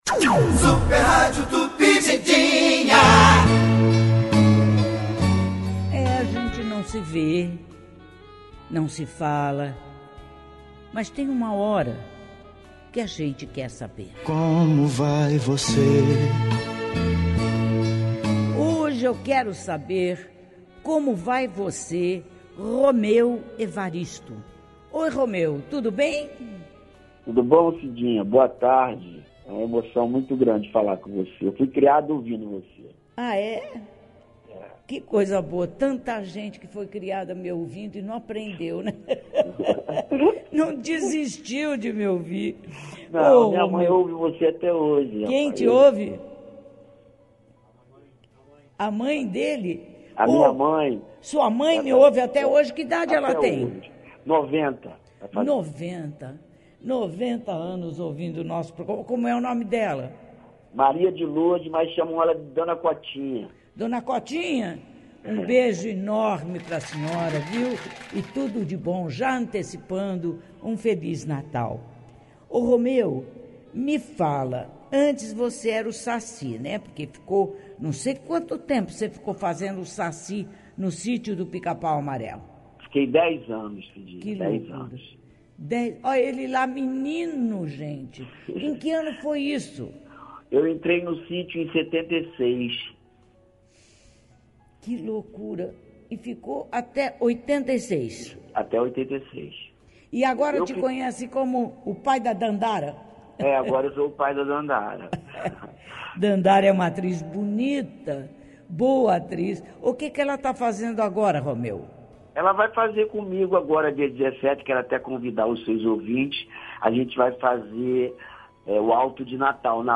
O ator e comediante brasileiro Romeu Evaristo foi o entrevistado do quadro ‘Como Vai Você’ desta terça-feira (14). Na conversa com Cidinha Campos, ele falou sobre sua vida pessoal e sua carreira.